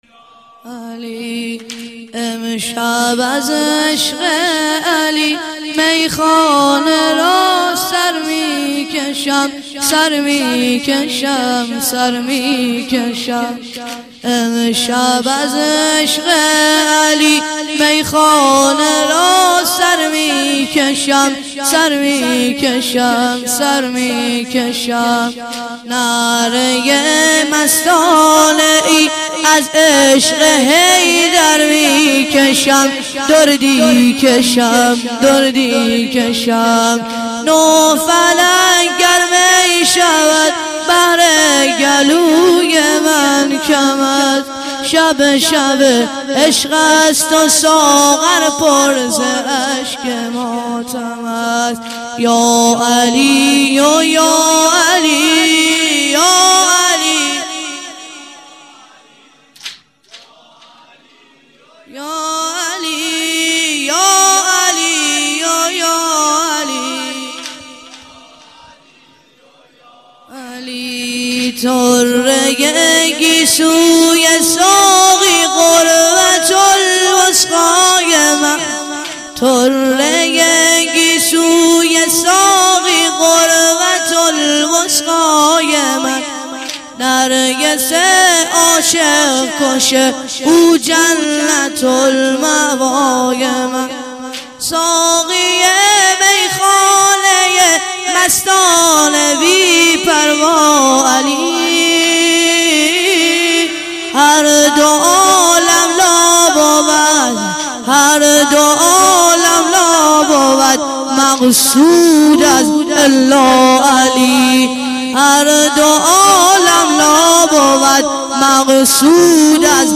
واحد - امشب از عشق علی - مداح